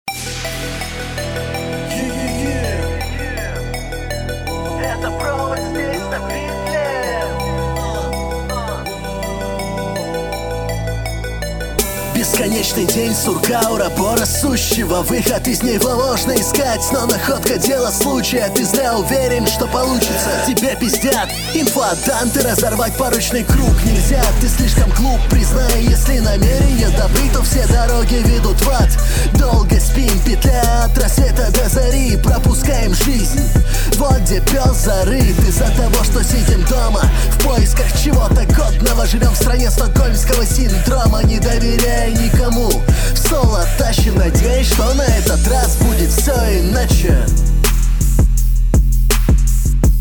Трек слушается, как очень старый.
Плохо взаимодействуешь с битом